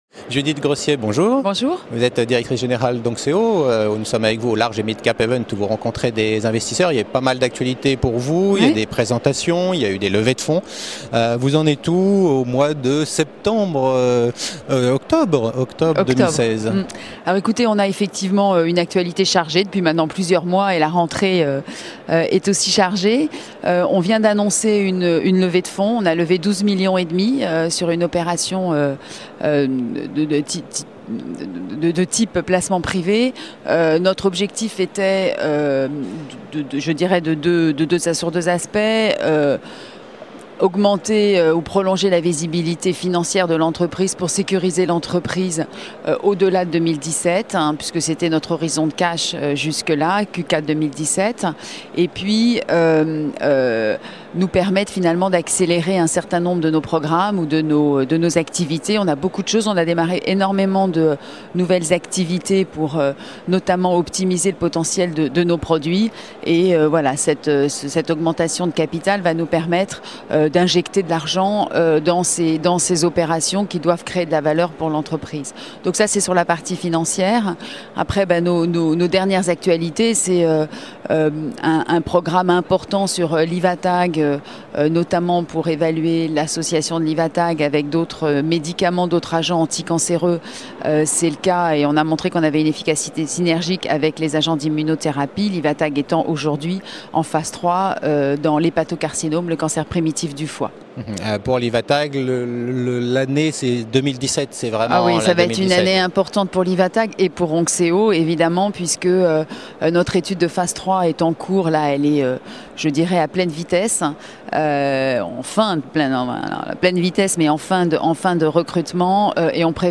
La Web TV au Midcap Event 2016 organisé par CF&B au Palais Brongniart à la rencontre des dirigeants d’entreprises qui présentent aux investisseurs leurs dernières actualités.